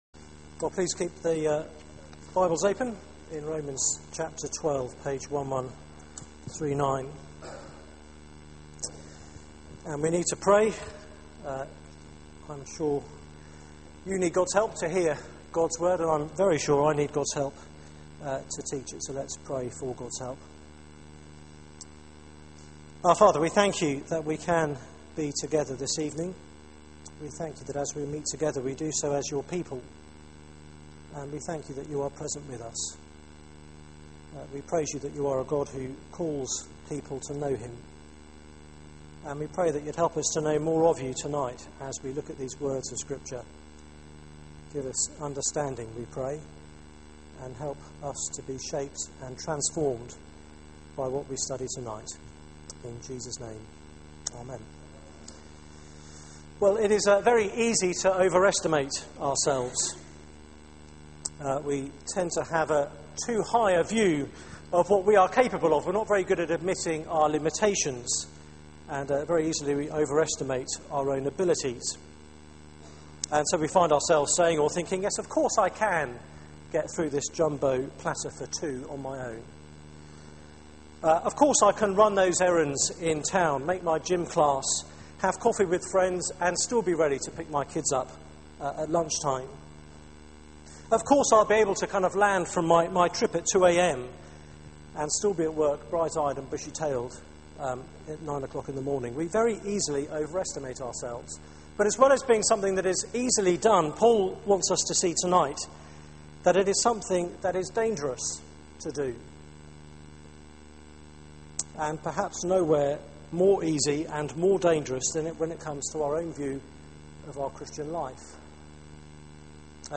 Media for 6:30pm Service on Sun 09th Sep 2012
Series: The Christian Life Theme: Our place in the church Sermon (poor sound quality)